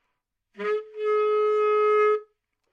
萨克斯管单音（演奏得不好） " 萨克斯管 A2 baddynamics
描述：在巴塞罗那Universitat Pompeu Fabra音乐技术集团的goodsounds.org项目的背景下录制。单音乐器声音的Goodsound数据集。 instrument :: sax_baritonenote :: A＃octave :: 2midi note :: 34microphone :: neumann U87tuning reference :: 442.0goodsoundsid :: 5371 故意扮演坏动态的一个例子
标签： 好声音 单注 多样本 Asharp2 萨克斯 纽曼-U87 男中音
声道立体声